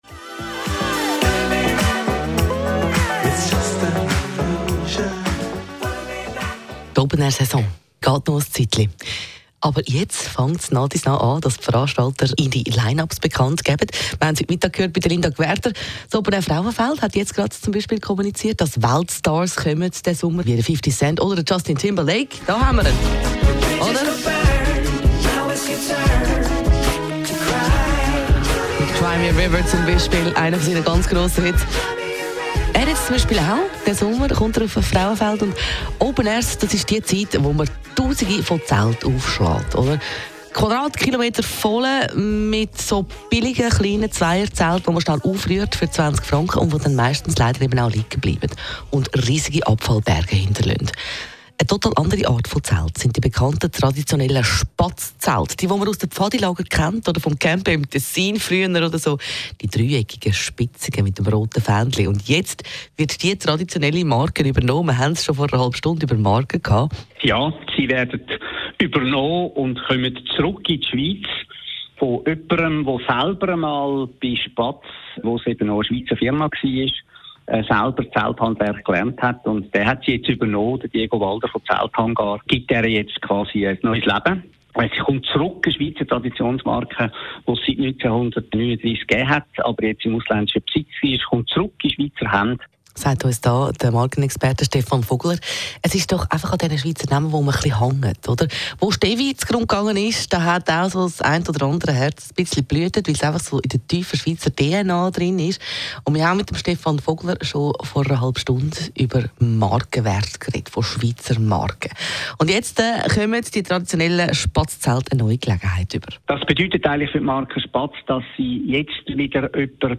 Interviews
Radio-Interview (Audio)